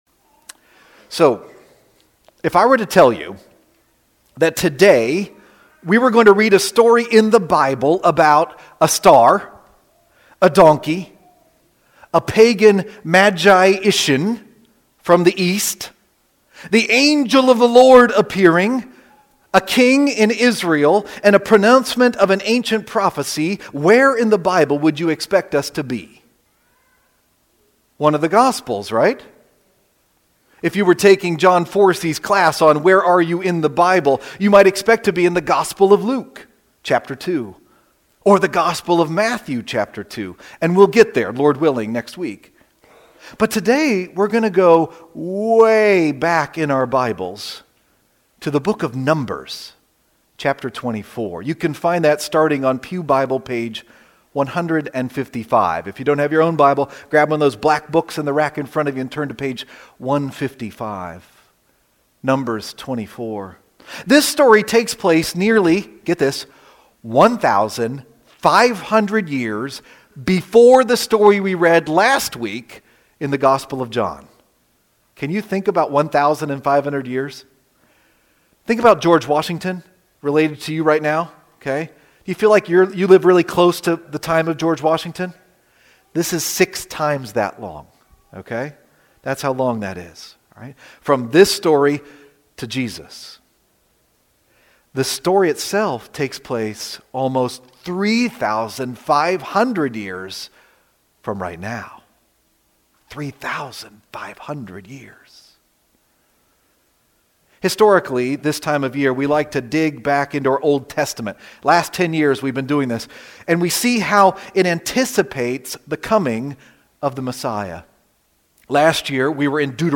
the sermon